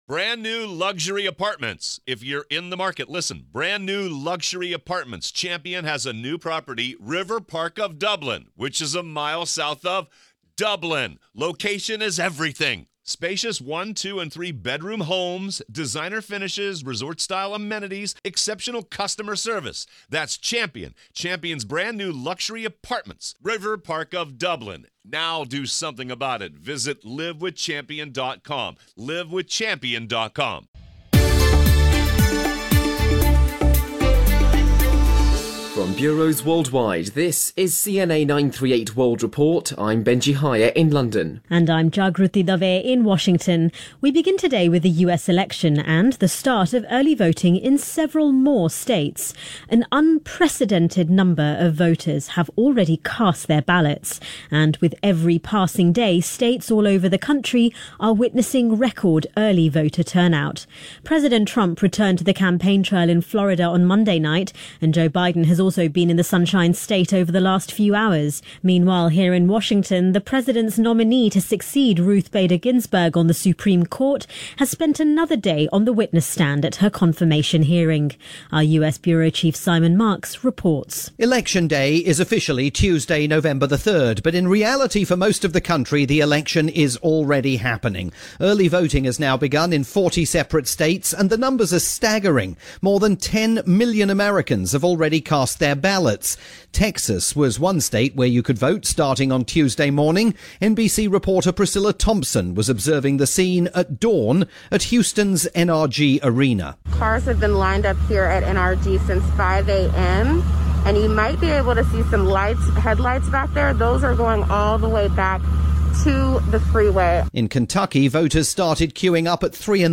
report airing on radio stations worldwide, and here via Singapore's CNA 938.